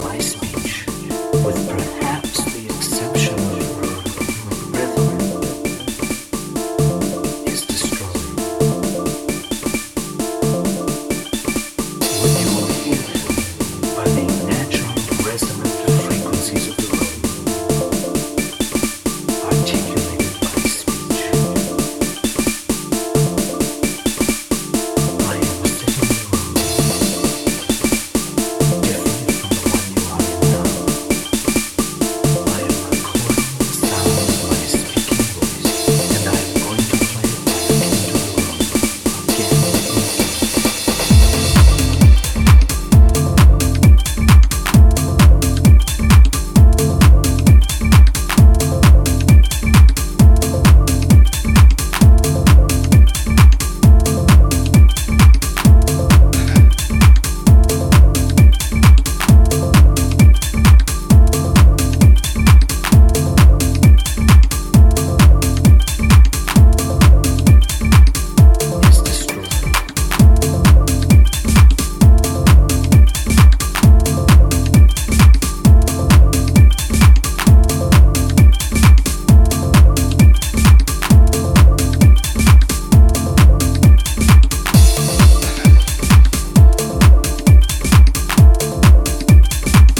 four-to-the-floor club music